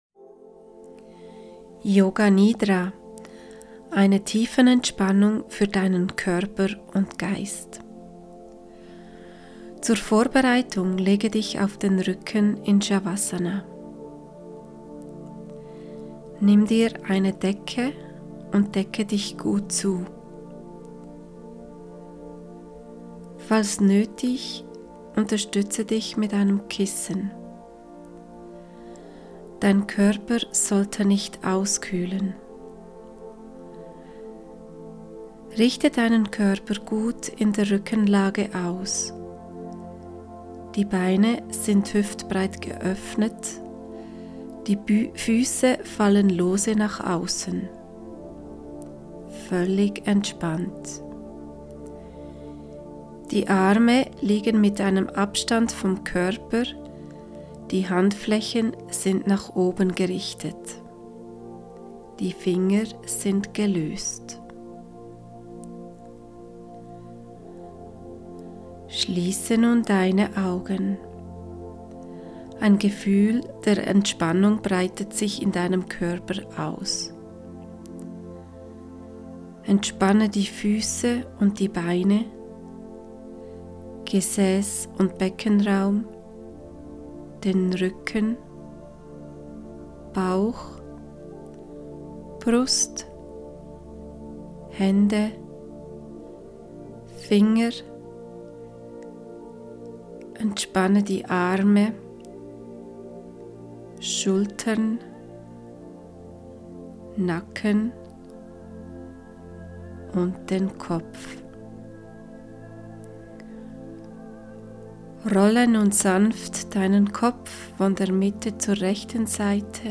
Hier eine Audiodatei mit einer Yoga Nidra-Übung.